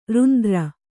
♪ rundra